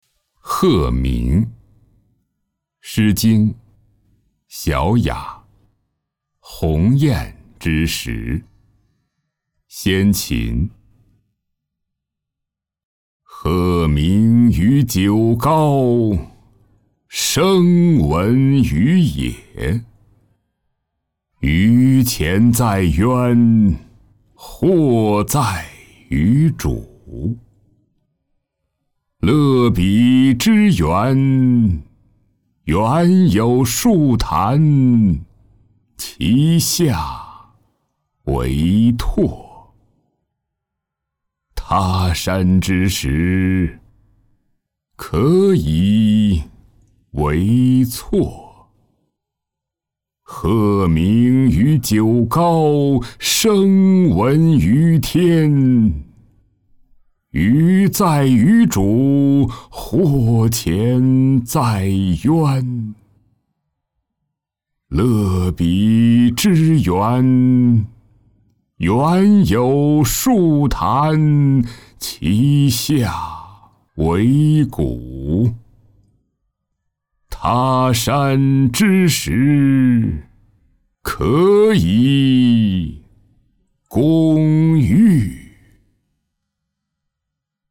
诵读客提供诗经·小雅·鹤鸣音频下载。